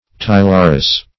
Search Result for " tylarus" : The Collaborative International Dictionary of English v.0.48: Tylarus \Ty"la*rus\, n.; pl.